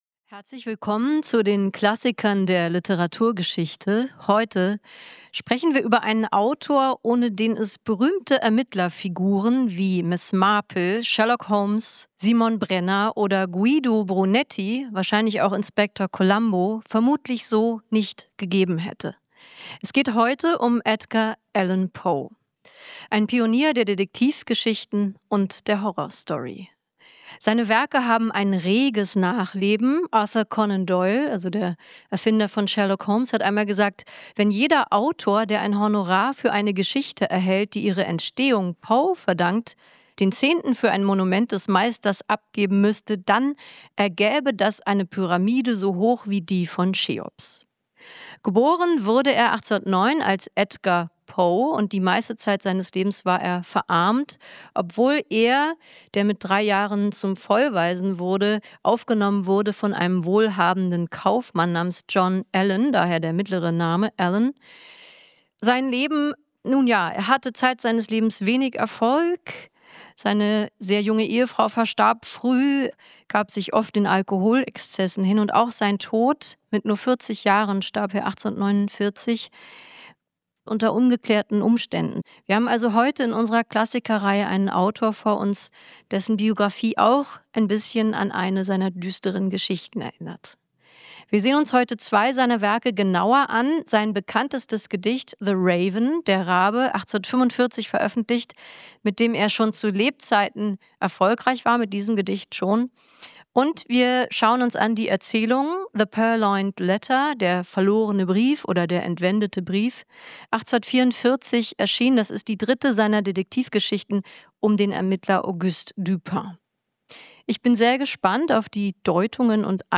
Mitarbeit Interviewte Person: Ulrike Draesner, John von Düffel